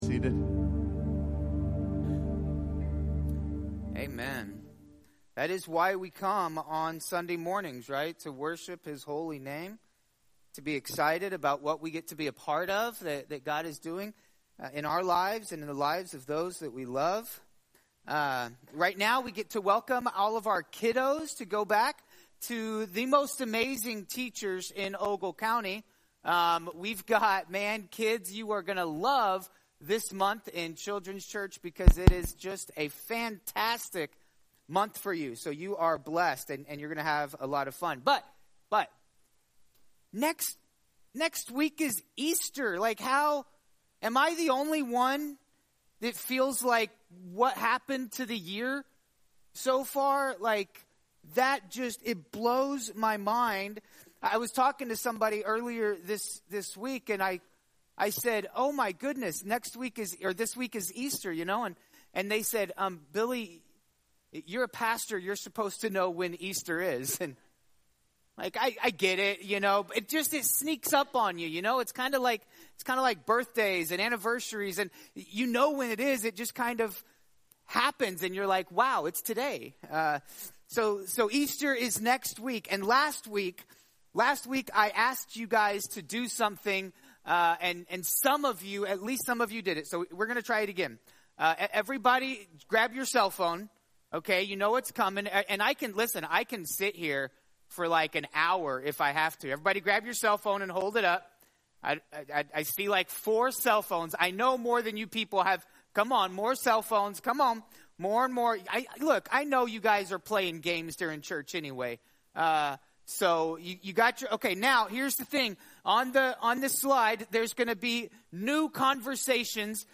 Messages | Leaf River Baptist Church